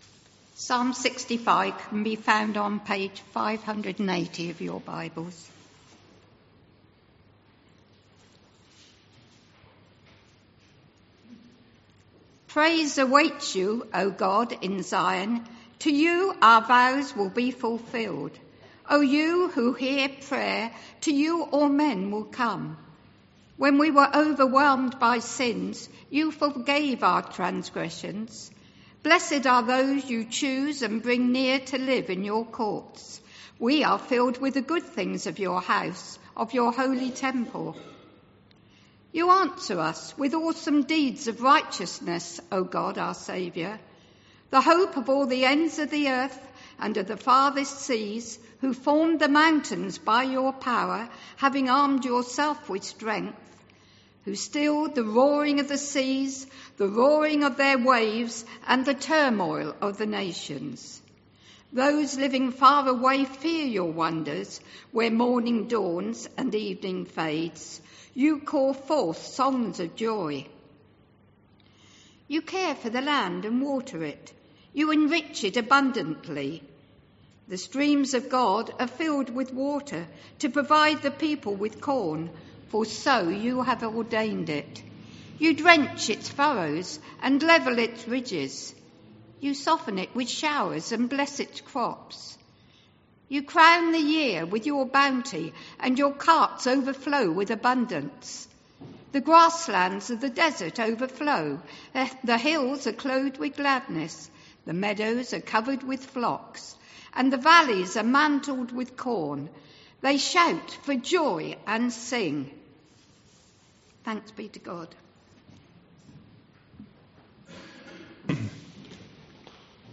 An audio file of the service is now available to listen to.